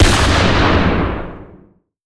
Sniper_Rifle4.ogg